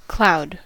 cloud: Wikimedia Commons US English Pronunciations
En-us-cloud.WAV